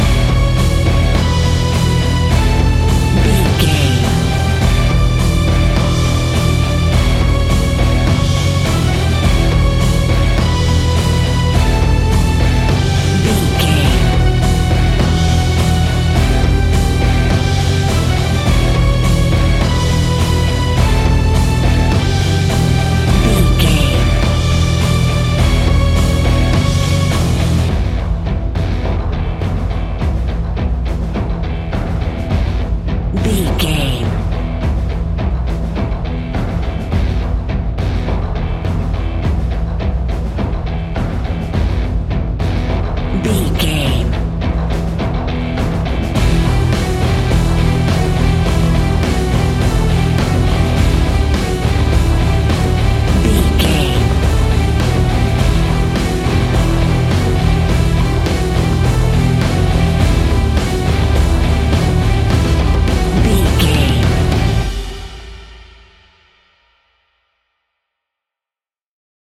Epic / Action
Fast paced
Aeolian/Minor
hard rock
instrumentals
Heavy Metal Guitars
Metal Drums
Heavy Bass Guitars